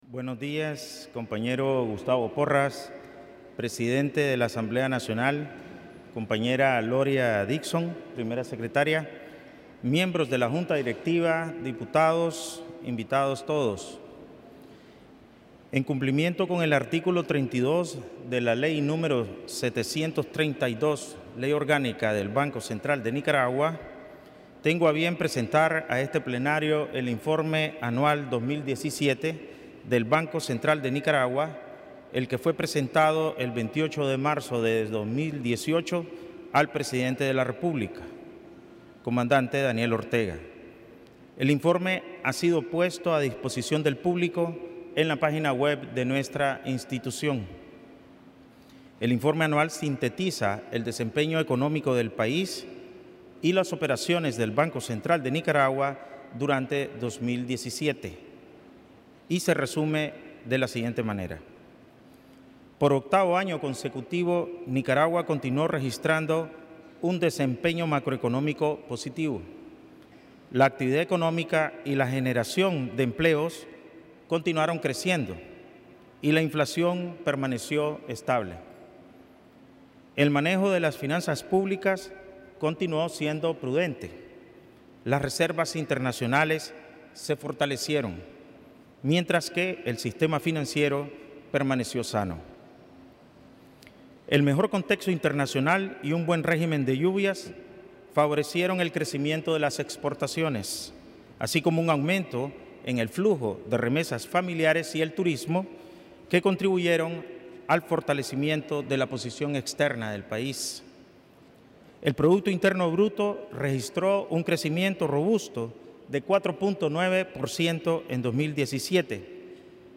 Presidente del BCN presenta Informe Anual 2017 en Asamblea Nacional
El Presidente del Banco Central de Nicaragua (BCN), Ovidio Reyes R., en uso de sus facultades, y de conformidad a lo establecido en el Artículo 138 numeral 29 de la Constitución Política de la República de Nicaragua, presentó el 10 de abril de 2018 ante la Asamblea Nacional, el Informe Anual 2017.